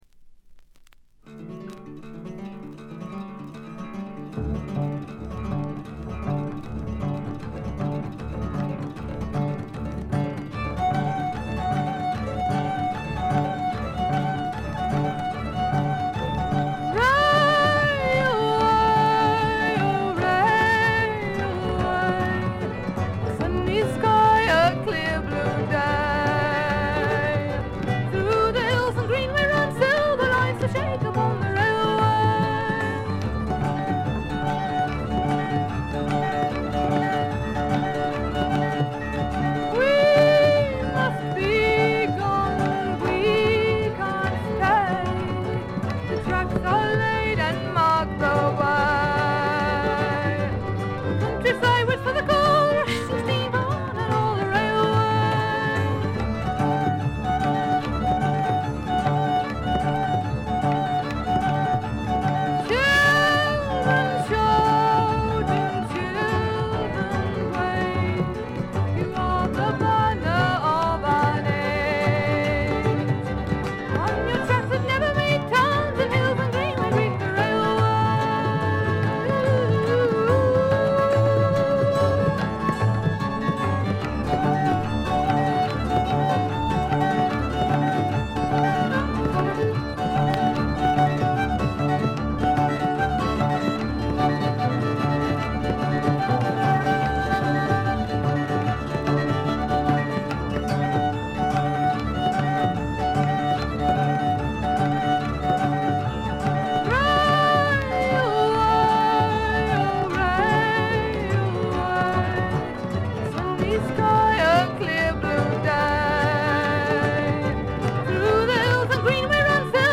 B1後半でプツ音1回（周りの音に掻き消されてわからないレベル）。
試聴曲は現品からの取り込み音源です。
Mandolin, Violin, Vocals